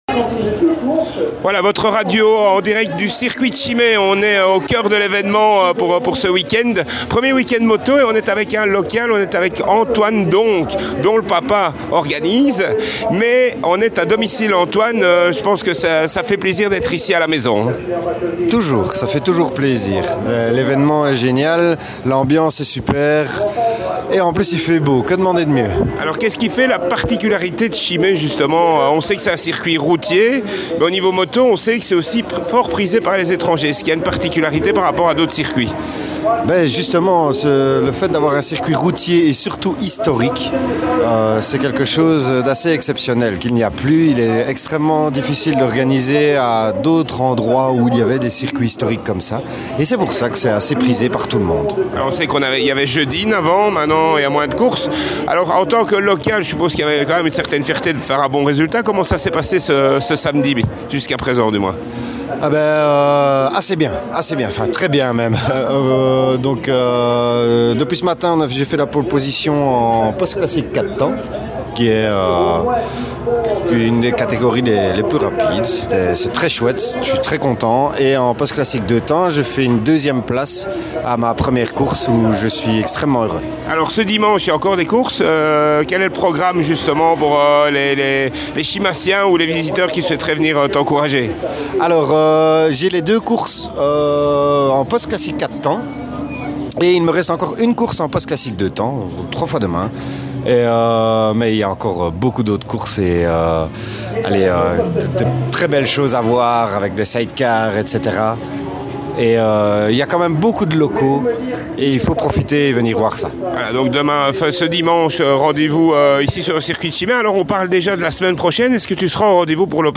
sur le circuit de CHIMAY pour le classic bikes.